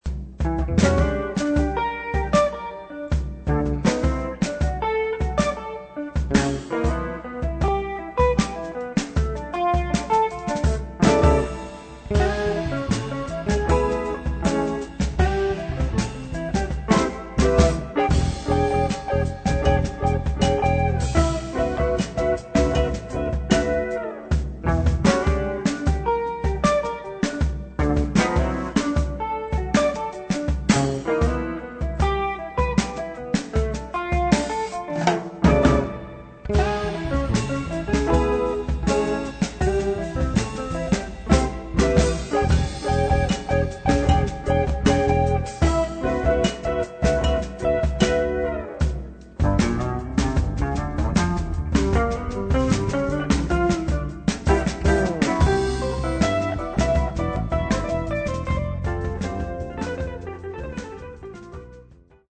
in eight funk and latin originals.
Guitar
Fretless Electric Bass
Drums